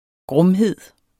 Udtale [ ˈgʁɔmˌheðˀ ]